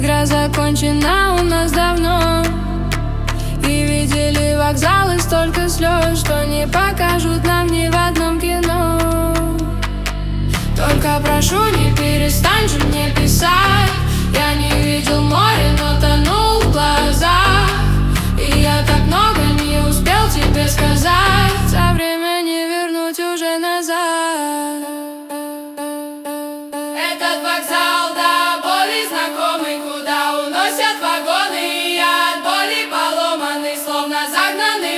Hip-Hop Hip-Hop Rap